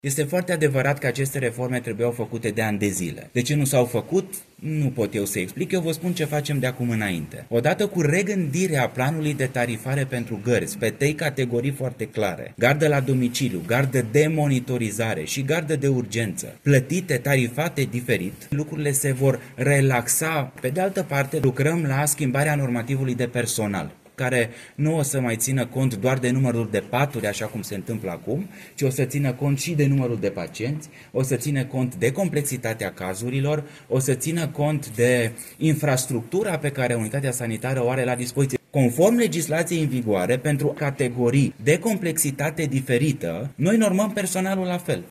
Prezent la Timișoara în vizită la Centrul de Mari Arși, ministrul Sănătății a confirmat că cele două reforme sunt în pregătire.
Răspunzând unei întrebări legate de lipsurile din sistemul sanitar şi despre suprasolicitărea cadrelor medicale până la epuizare, ministrul Sănătății a declarant că se lucrează la schimbarea normativului de personal și la regândirea planului de tarifare pentru gărzi.